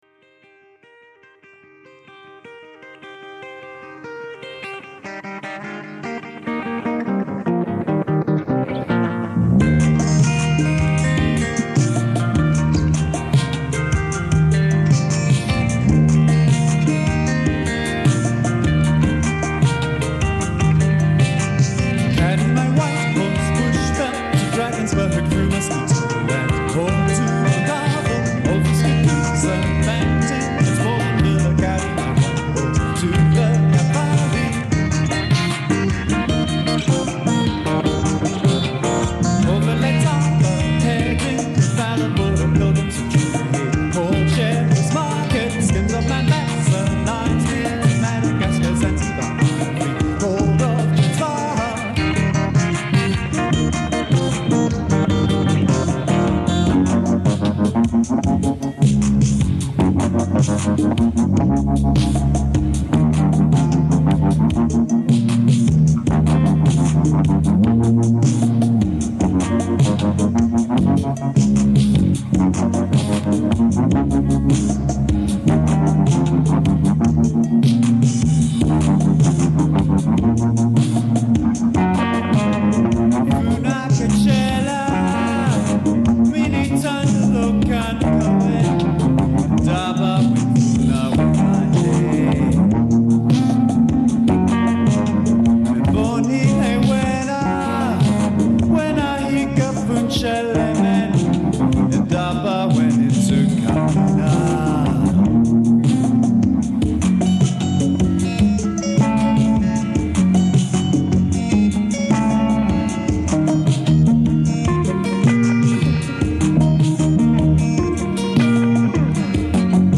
A four-track tape machine was used in an earthy fashion for the recording.
A sense of uprooted wilderness in these songs owes something to the experience of two colonial boys transplanted.